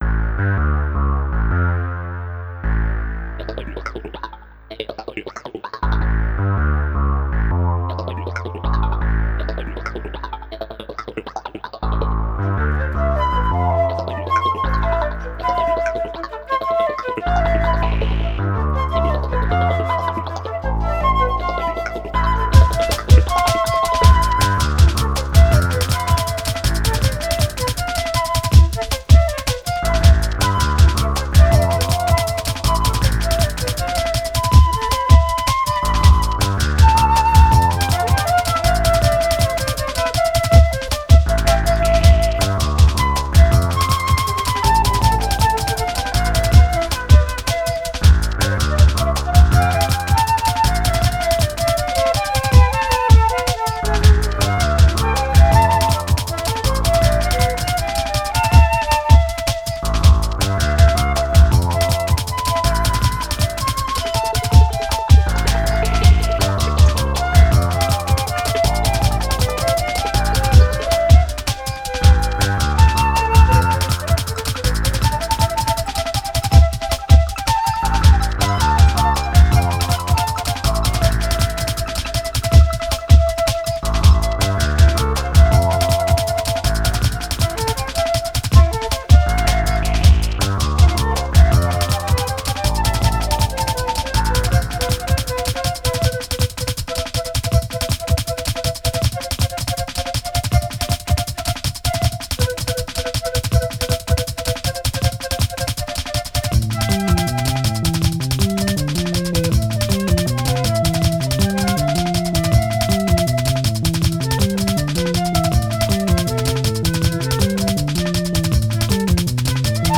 Instrumentation: Two flutes and electronics